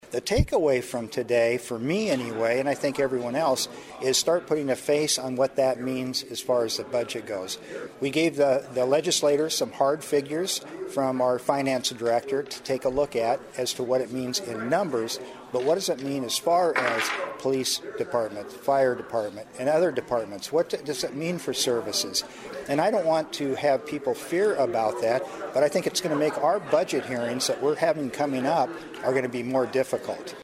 MEMBERS OF THE CITY COUNCIL OF SIOUX CITY AND VARIOUS DEPARTMENT HEADS MET WITH LOCAL IOWA STATE LAWMAKERS FRIDAY MORNING TO DISCUSS ISSUES AND PRIORITIES FOR THE UPCOMING STATE LEGISLATIVE SESSION.
MAYOR PRO-TEM DAN MOORE SAYS ONE STRONG EXAMPLE THE CITY PRESENTED WAS THAT WITH THE STATE REDUCING WHAT THE CITY RECEIVES IN FUNDING, THEY WOULD HAVE TO CUT 25 POLICE OR FIREFIGHTER POSITIONS TO BREAK EVEN WITH LAST YEAR’S TAX LEVY;